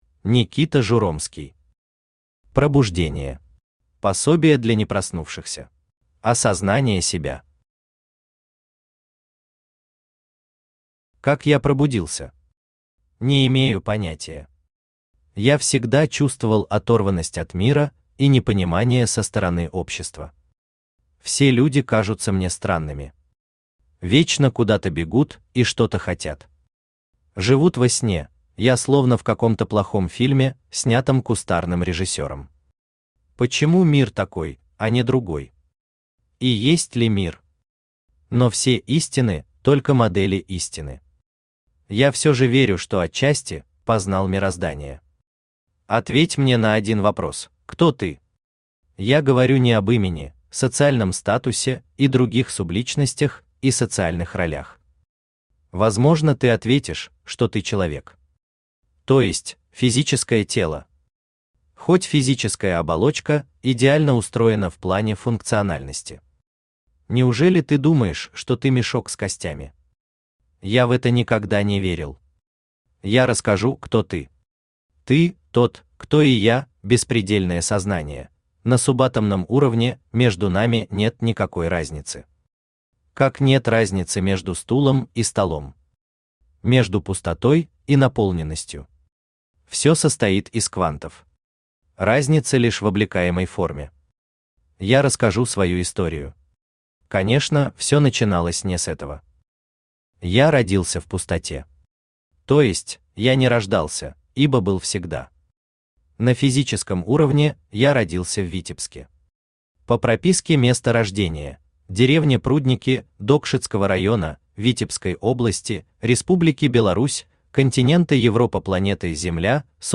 Пособие для непроснувшихся Автор Никита Журомский Читает аудиокнигу Авточтец ЛитРес.